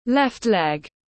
Chân trái tiếng anh gọi là left leg, phiên âm tiếng anh đọc là /left leg/.
Left leg /left leg/